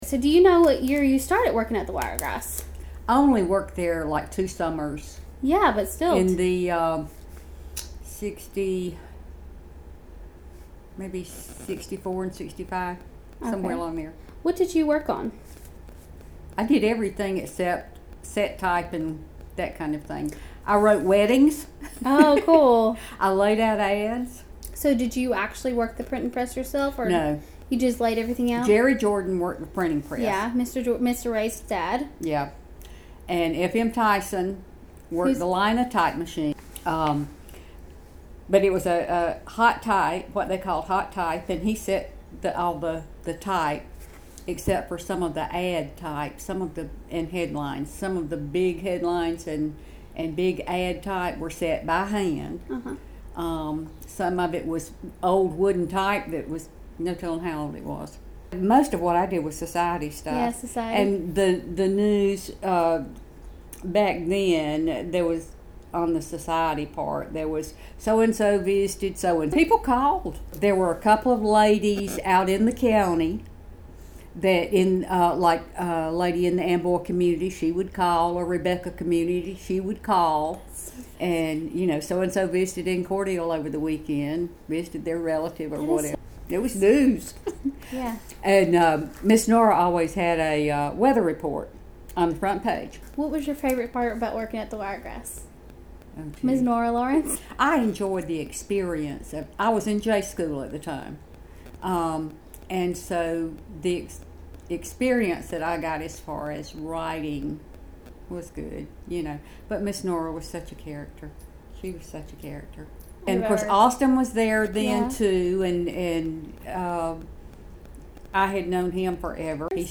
Oral History Item Type Metadata
Interviewer
Interviewee
Sycamore, Georgia